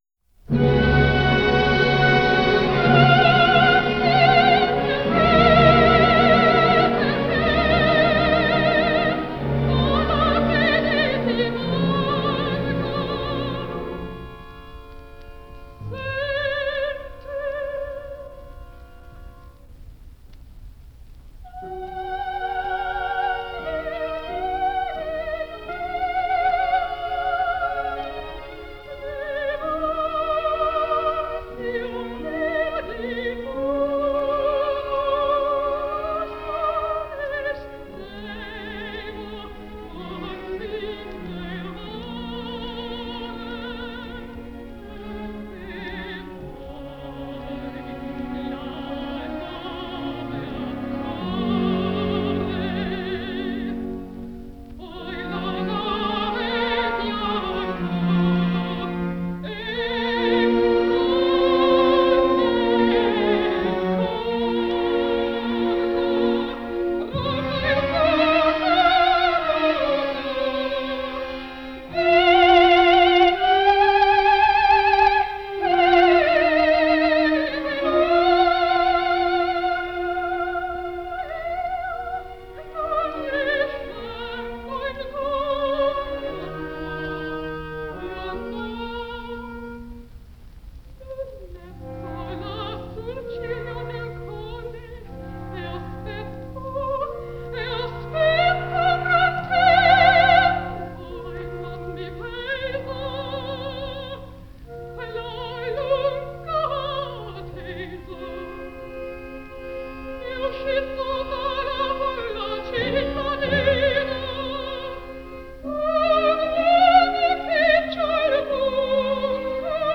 101 год со дня рождения американской певицы (сопрано), педагога Элеанор Стебер (Eleanor Steber)
03 - Eleanor Steber - MADAMA BUTTERFLY (Puccini) - Act II - Un bel di vedremo (June 26, 1940)